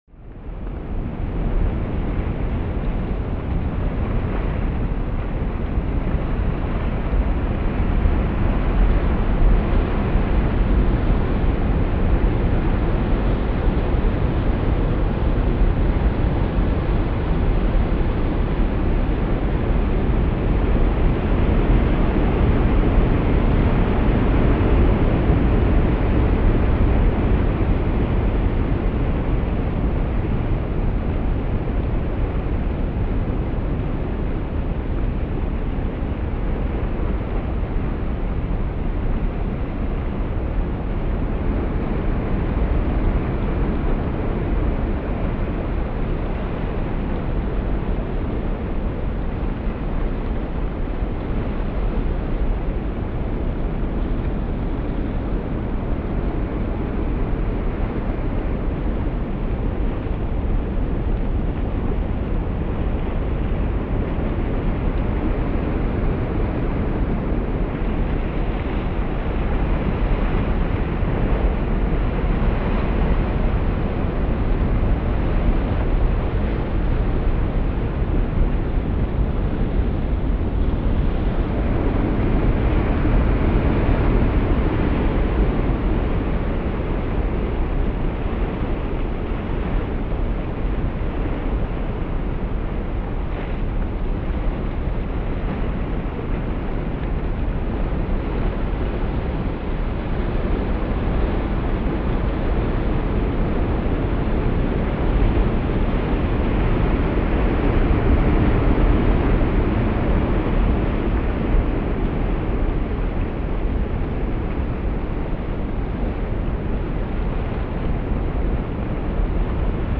دانلود آهنگ وال 11 از افکت صوتی انسان و موجودات زنده
جلوه های صوتی
دانلود صدای وال 11 از ساعد نیوز با لینک مستقیم و کیفیت بالا